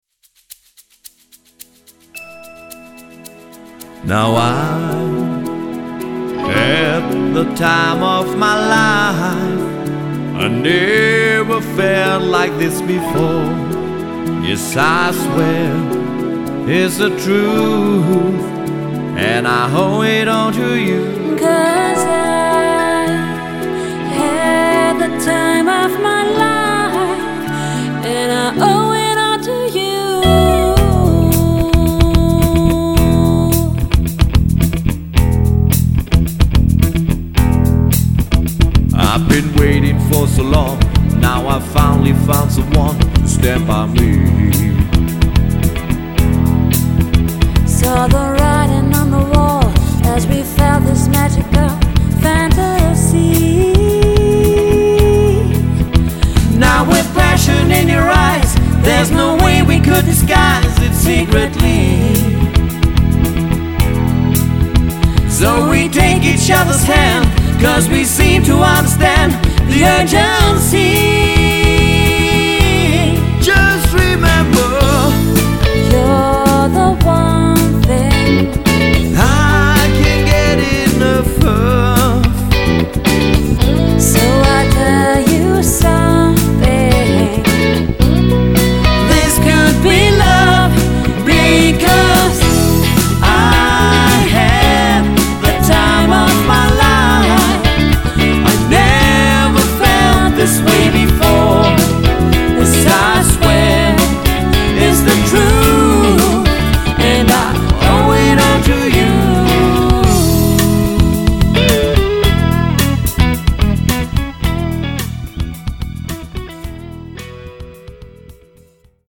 Trio oder Quartett – Sängerin u. Sänger